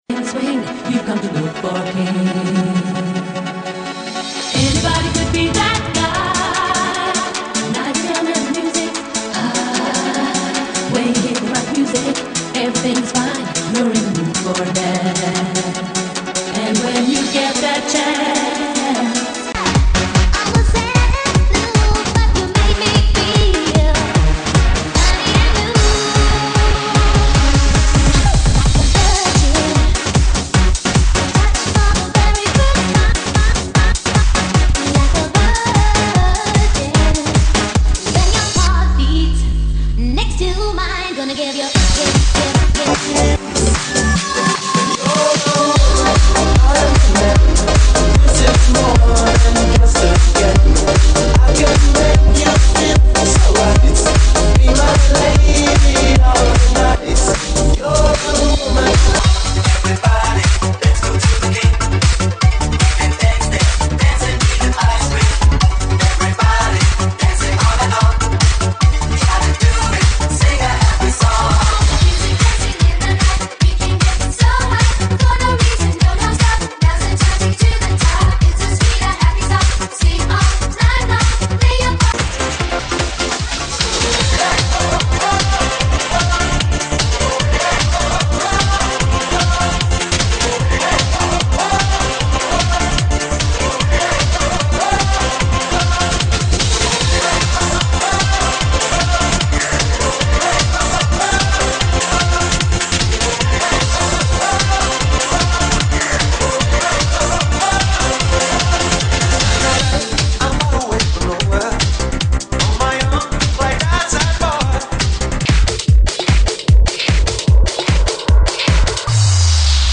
GENERO: MUSICA DISCO, 80S, 90S